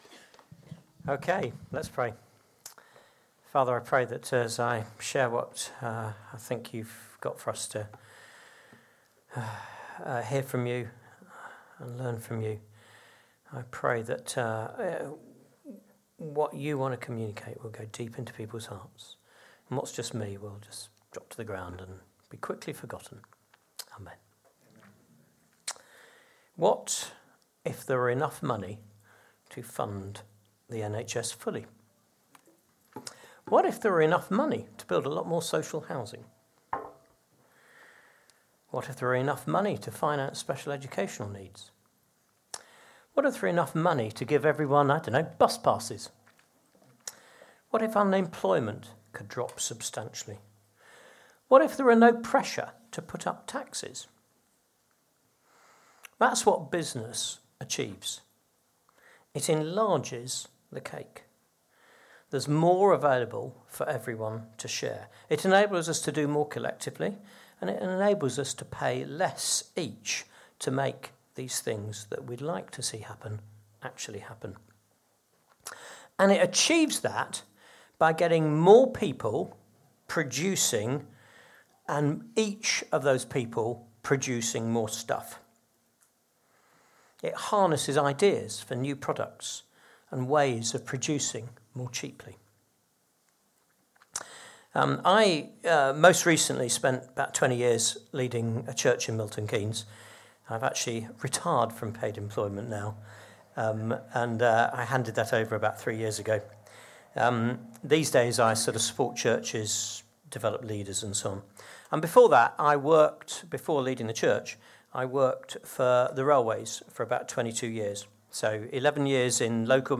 Download Sent Into Business | Sermons at Trinity Church